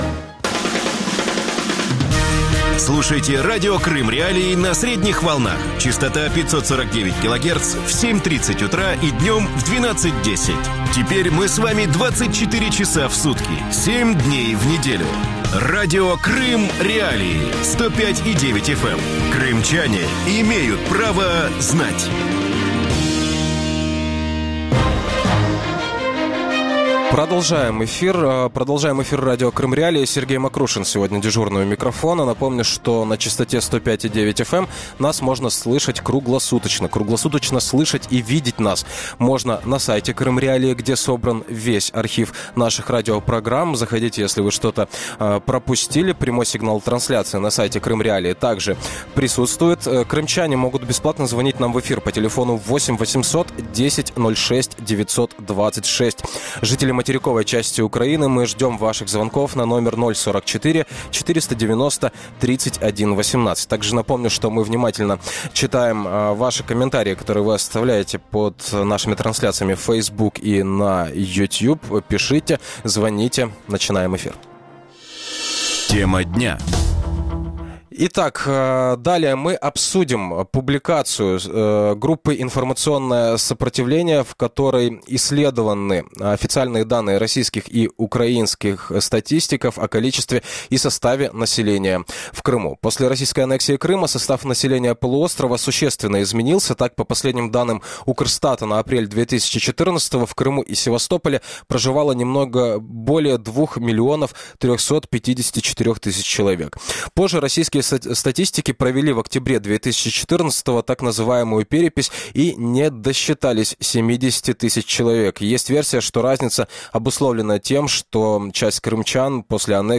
Можно ли говорить о целенаправленном замещении населения в Крыму? Гости эфира